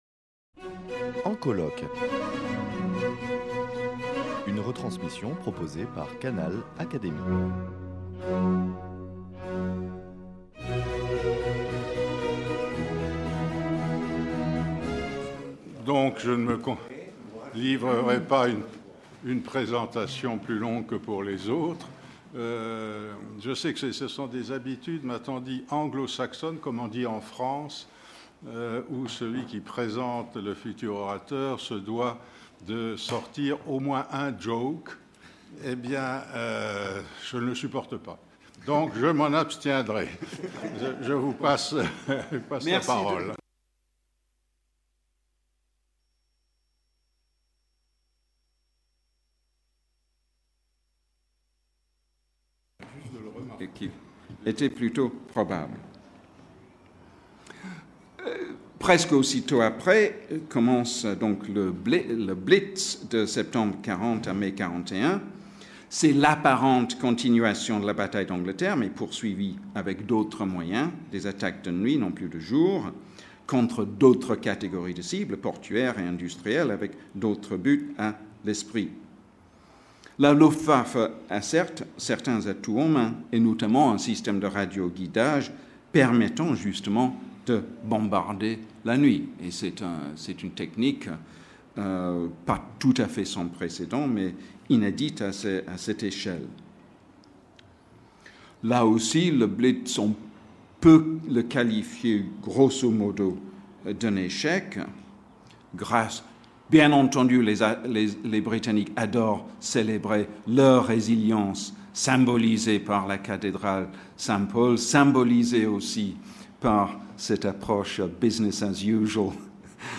Retransmission du colloque international « La guerre et le droit » - Partie 7